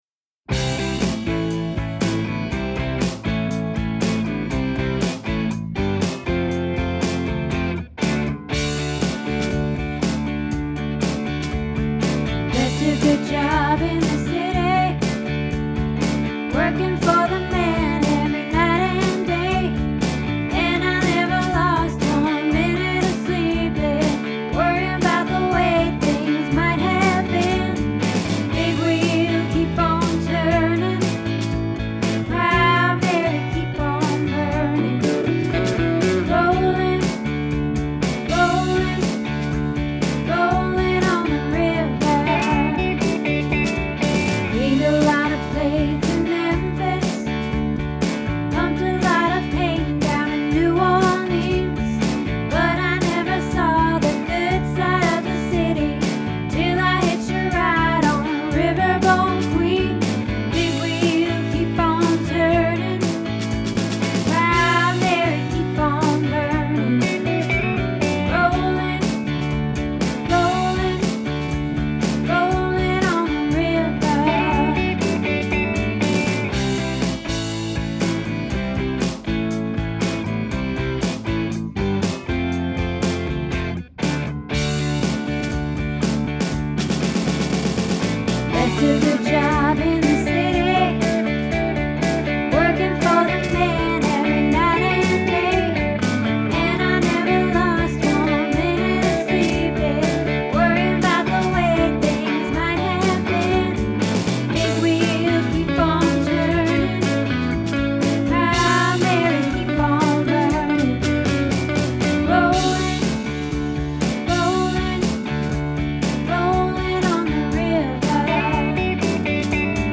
Proud Mary - Alto